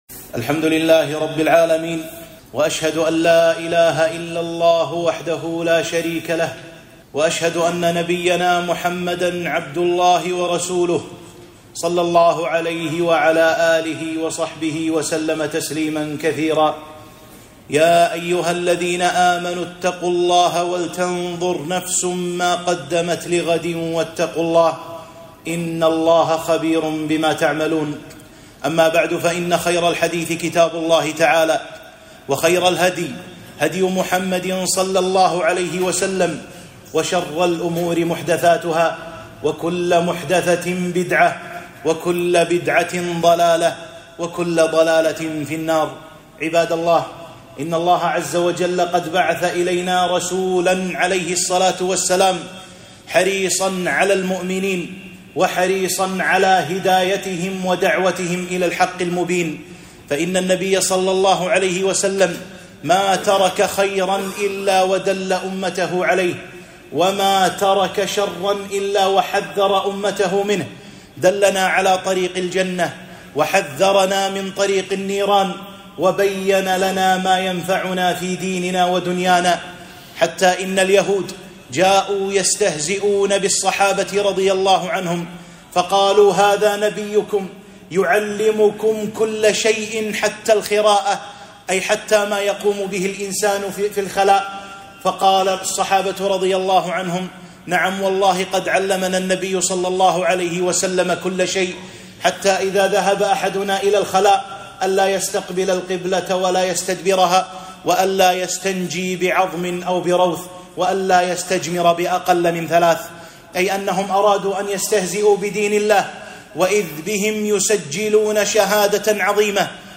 خطبة - السبع الموبقات